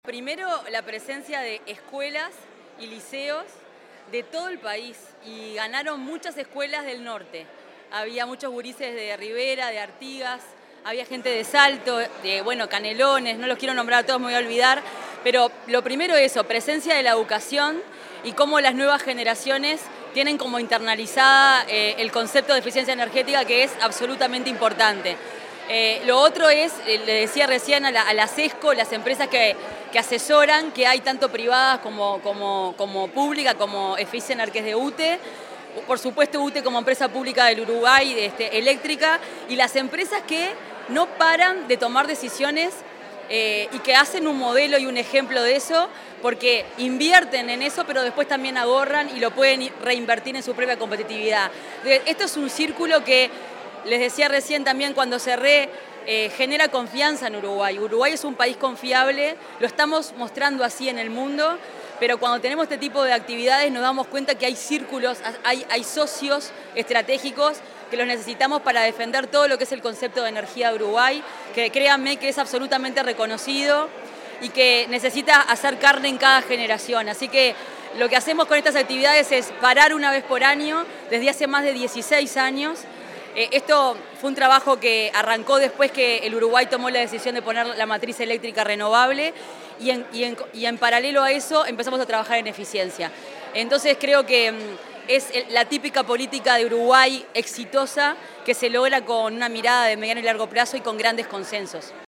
Declaraciones de la ministra de Industria, Fernanda Cardona
Declaraciones de la ministra de Industria, Fernanda Cardona 27/11/2025 Compartir Facebook X Copiar enlace WhatsApp LinkedIn En el marco de la ceremonia de Entrega del Premio Nacional de Eficiencia Energética, la ministra de Industria, Energía y Minería, Fernanda Cardona, realizó declaraciones a la prensa.